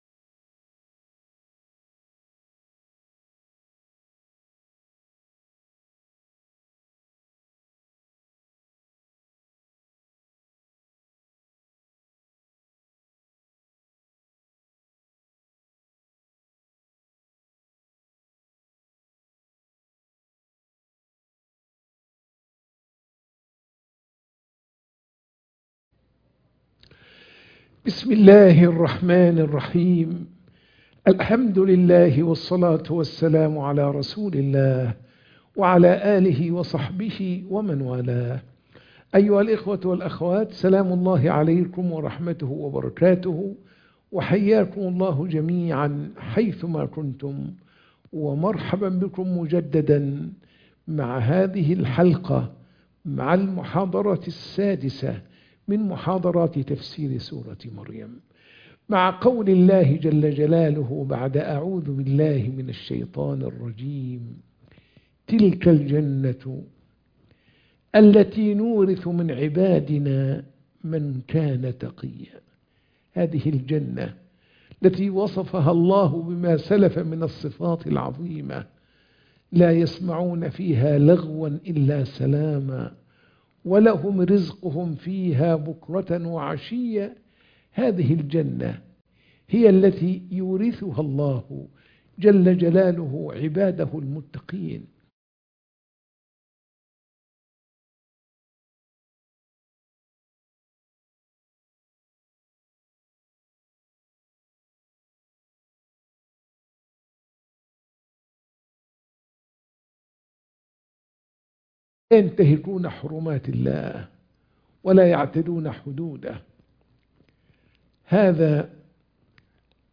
سورة مريم - المحاضرة 6